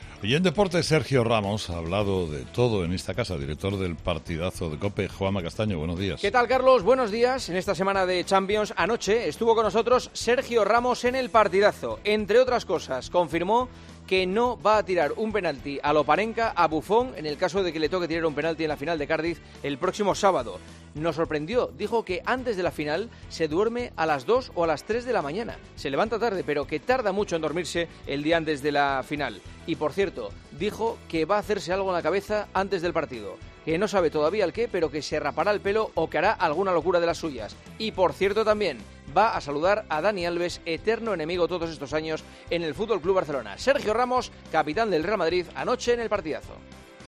AUDIO: La entrevista a Sergio Ramos en 'El Partidazo', en la actualidad deportiva con Juanma Castaño.